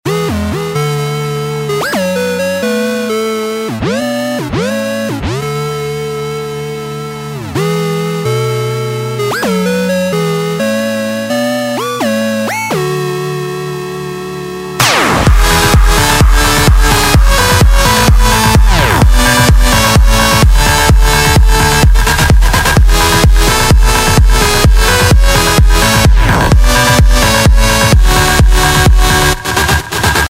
• Качество: 128, Stereo
progressive house